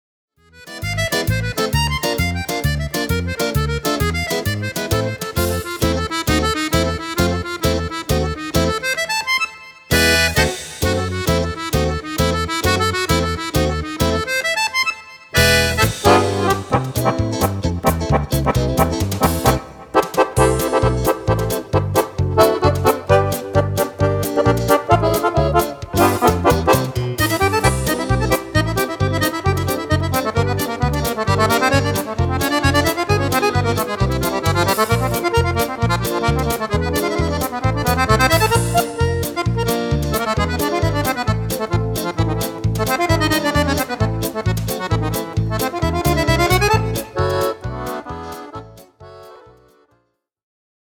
Tarantella
Fisarmonica